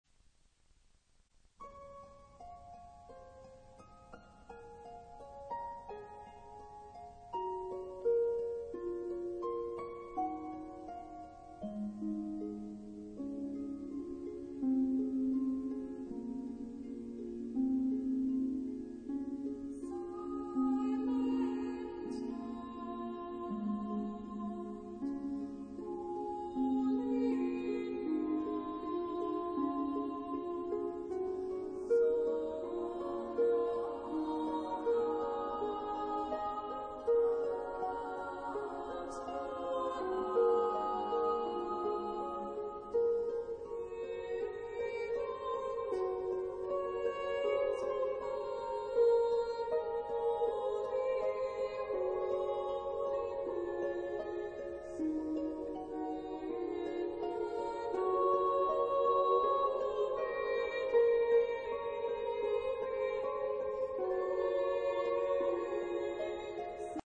Genre-Stil-Form: geistlich
Chorgattung: SSAA  (4 Frauenchor Stimmen )
Solisten: Mezzo-soprano (1)  (1 Solist(en))
Instrumente: Harfe (1)
Tonart(en): Des-Dur